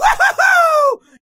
Cheer1.wav